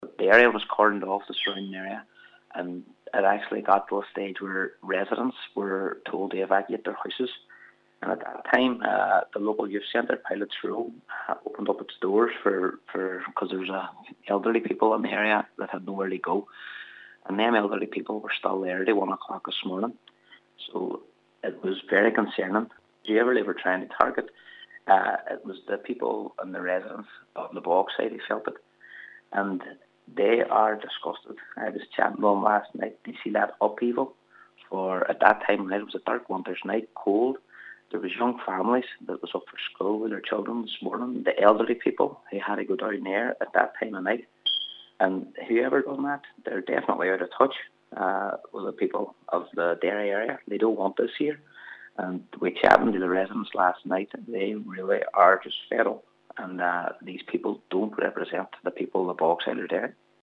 Councillor Colly Kelly says the people behind these attacks, provide nothing positive to the community: